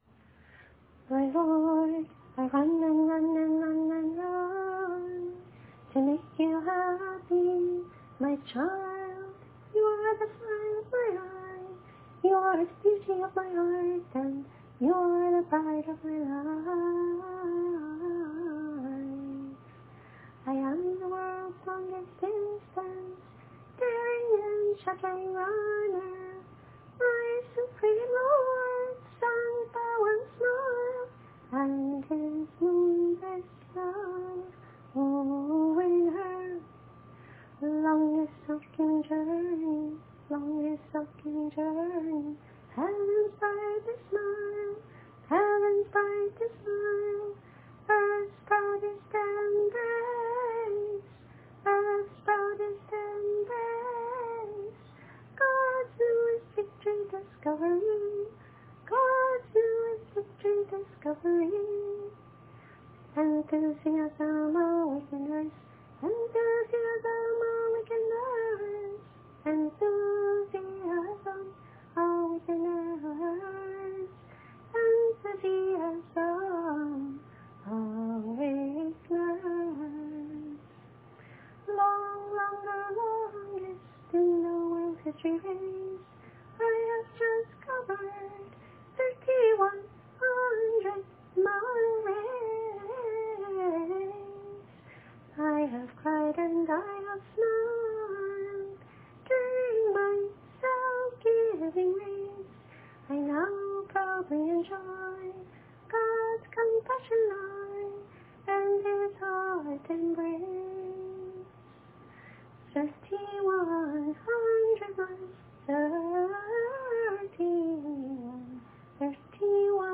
Selected songs we sing
During the race we sing through a total of about 210 short, energetic songs composed by Sri Chinmoy, which I’ve divided into sets (‘playlists’) of about 8-12 songs that we sing on a given day.
(again, this is a *practice* recording and contains imperfections)